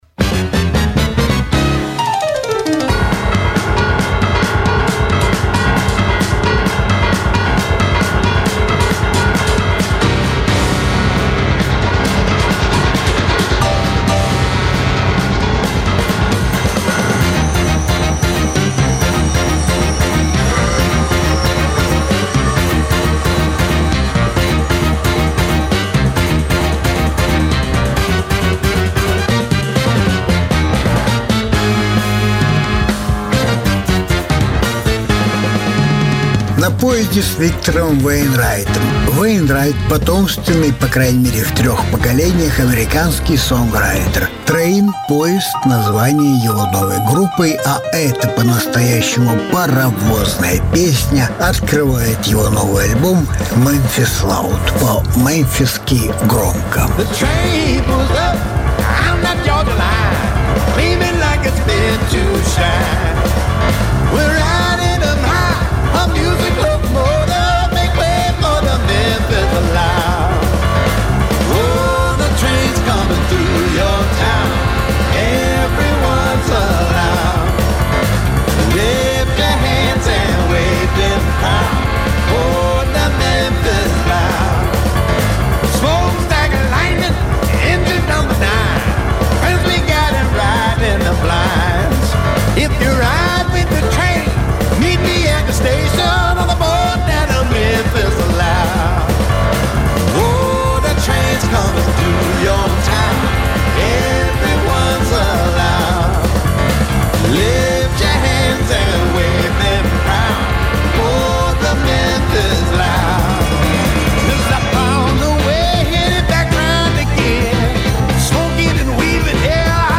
Разные альбомы Жанр: Блюз СОДЕРЖАНИЕ 17.08.2020 Блюзовые новинки 2020 года.